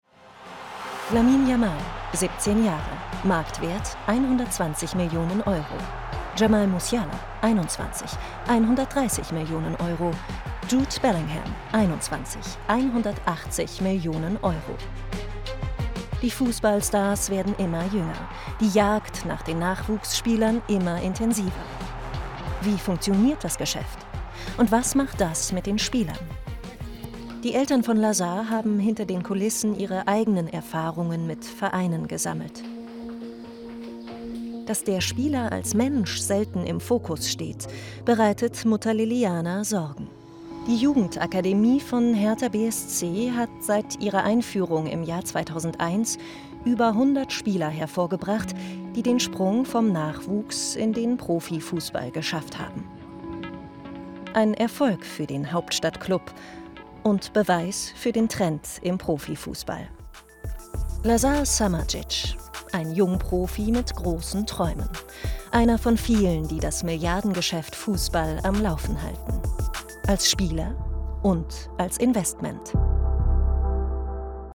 sehr variabel, hell, fein, zart
Comment (Kommentar)